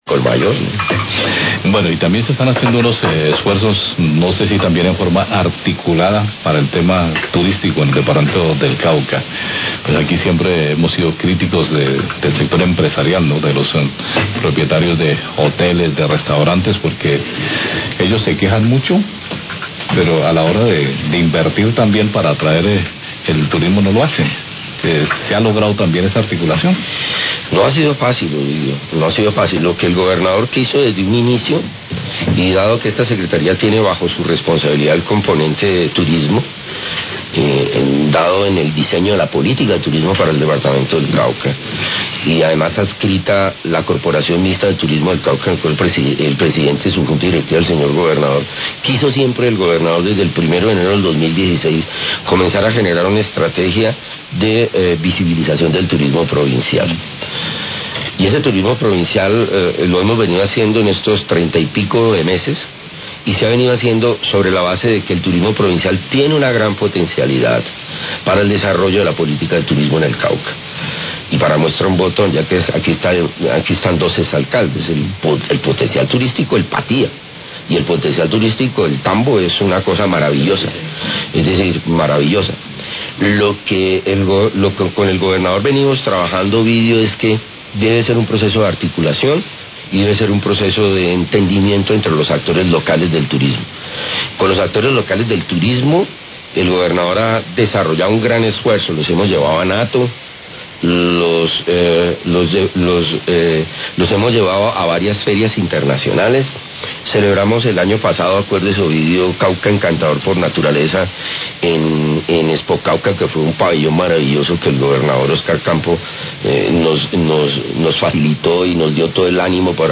Radio
Proponentes que iniciarán la construcción del embarcadero turístico San Martín en La Salvajina, visitaron el sector y se espera que este terminado al finalizar el año 2019. Declaraciones del titular de la Secretario de Desarrollo Económico y Competitividad del Cauca, Juan Carlos Maya..